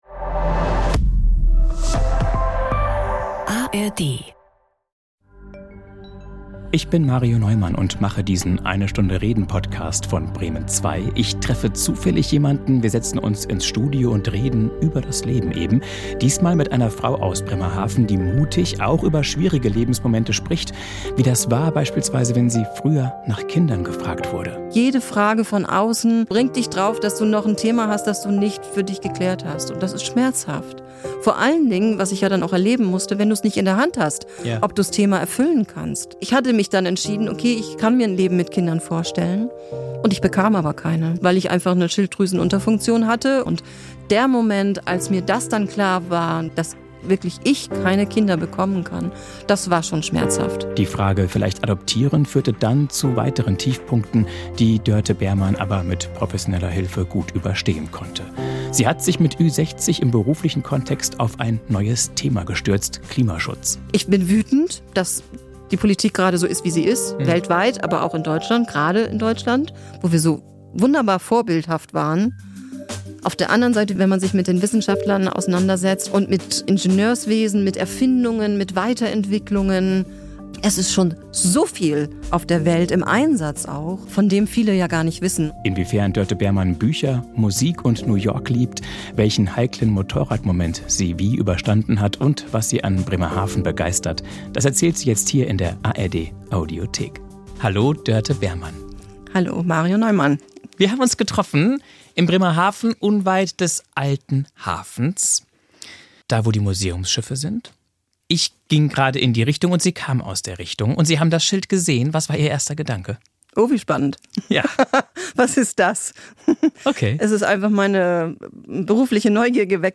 Beim Klima kennt sie kein Pardon ~ Eine Stunde reden – Gespräche mit Unbekannten Podcast